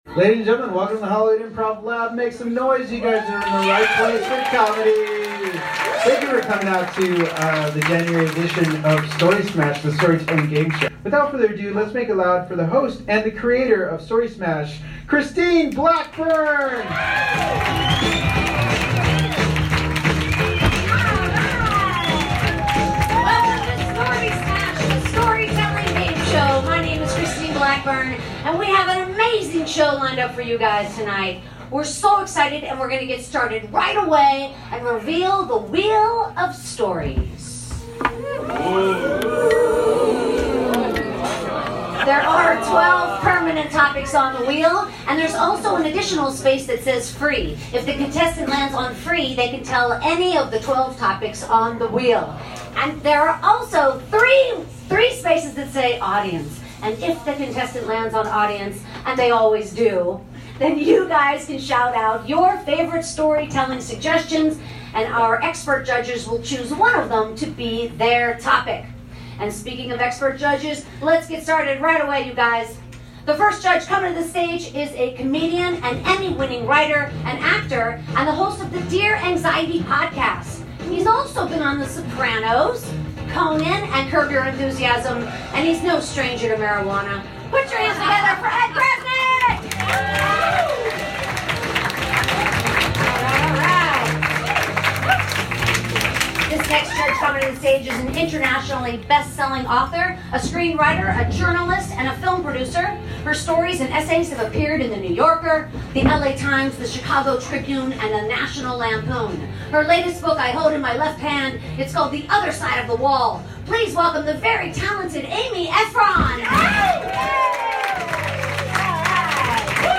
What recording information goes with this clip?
604 - Story Smash the Storytelling Game Show LIVE at The Hollywood Improv! Recorded January 25th at The Hollywood Improv.